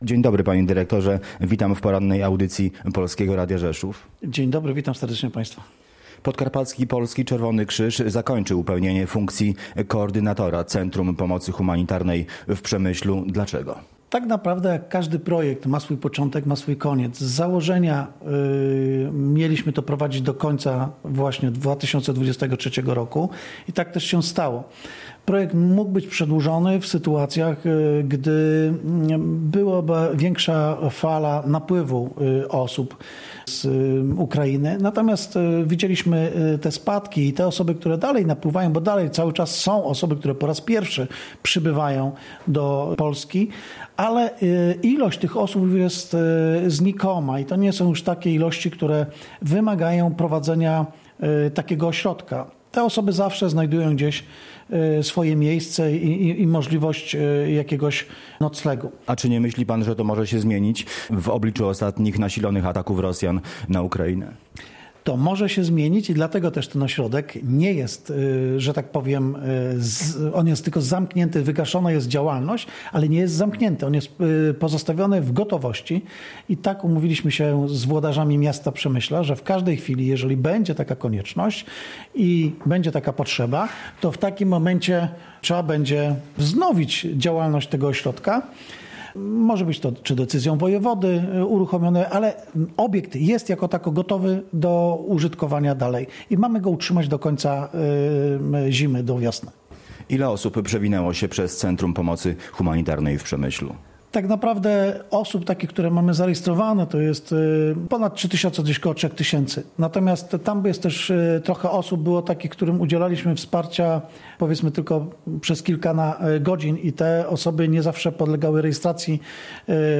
05_01_gosc_dnia.mp3